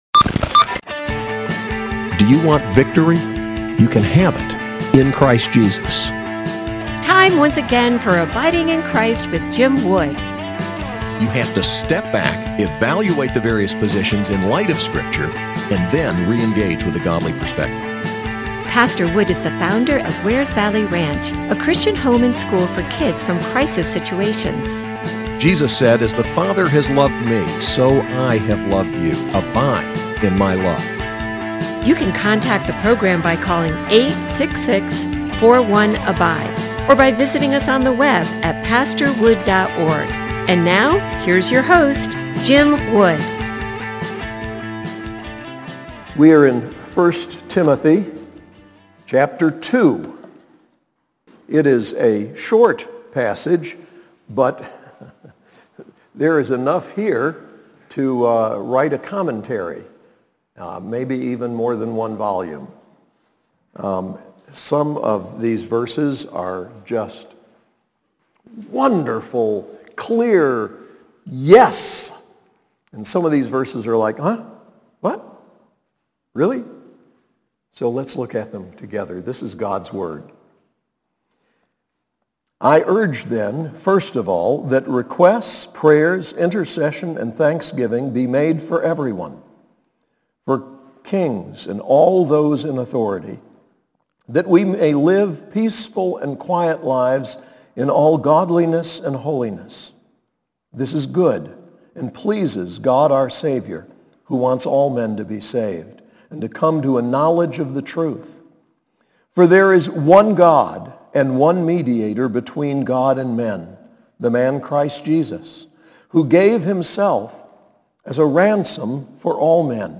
SAS Chapel: 1 Timothy 2